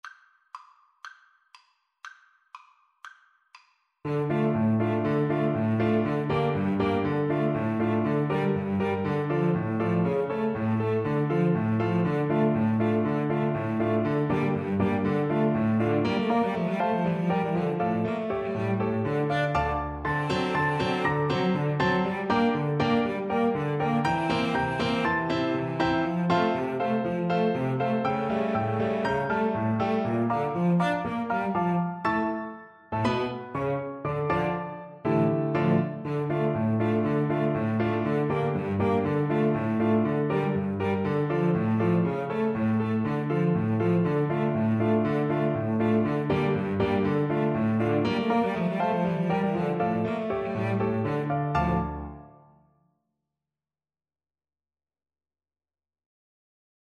Classical Brahms, Johannes Hungarian Dance no.5 Piano Trio version
ViolinCelloPiano
This piece comes from a set of 21 lively dance tunes based mostly on Hungarian themes.
D minor (Sounding Pitch) (View more D minor Music for Piano Trio )
2/4 (View more 2/4 Music)
Allegro (View more music marked Allegro)
Classical (View more Classical Piano Trio Music)